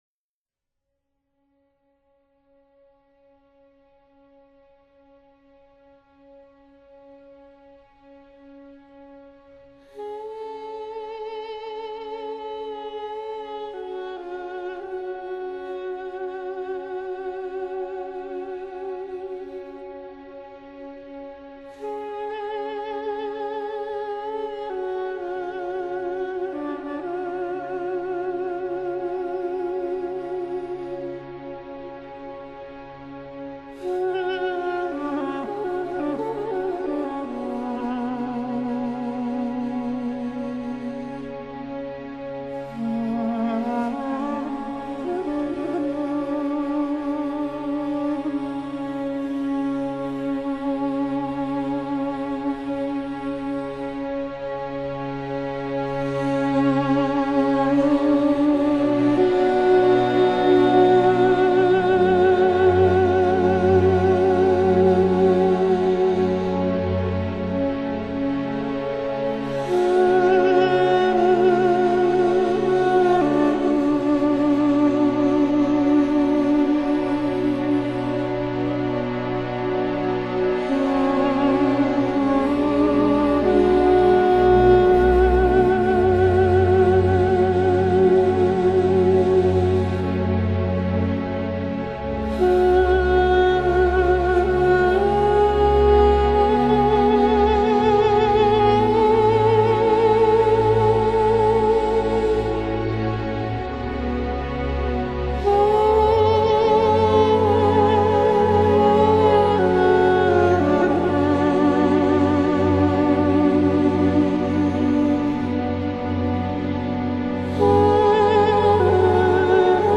其声音苍凉，孤独，带着浓浓的悲剧色彩。
都都克笛 ( duduk )是源自亚美尼亚古老的一种木制圆柱状乐器，据西方学者考究，已存于
音色方面，都都克笛 较温暖，柔和，略带鼻音，目前被运用在民谣与舞曲音乐，若有